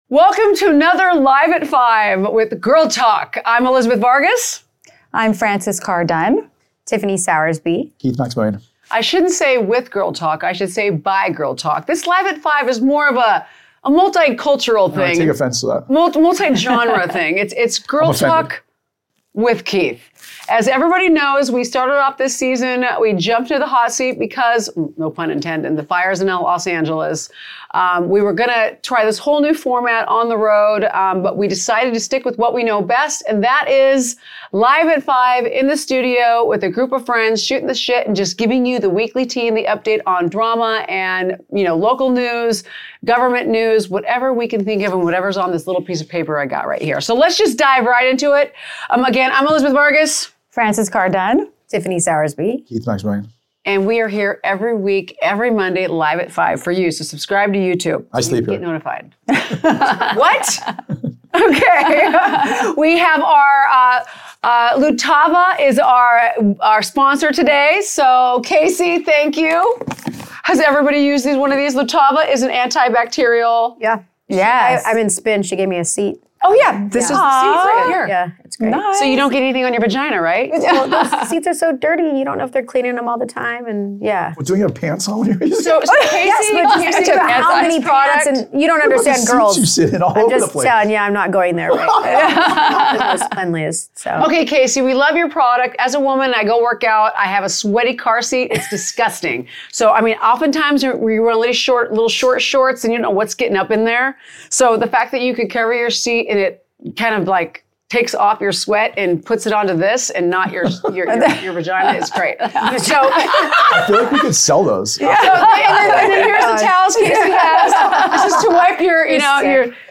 Girl Talk Logic - 🔥 LIVE from Newport Beach! Local News, Real Estate, The Grammys | Real Talk for Real Life